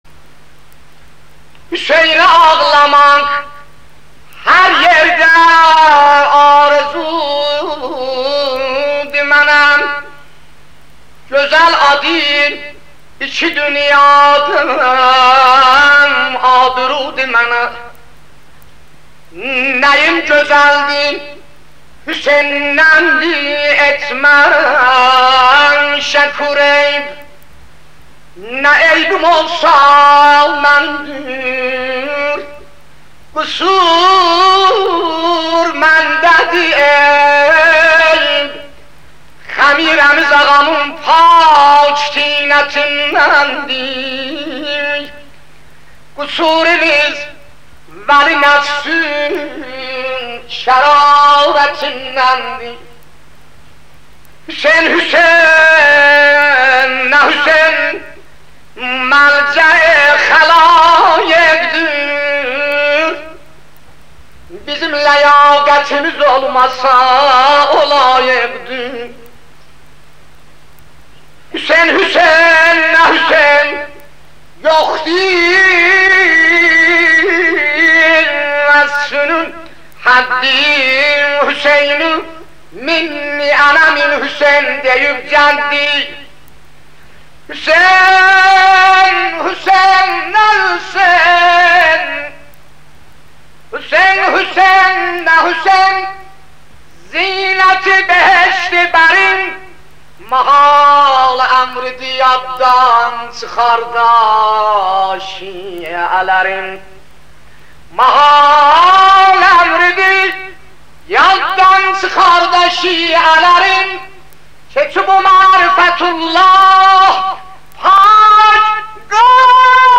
روضه و مرثیه ها
نوحه ترکی مداحی ترکی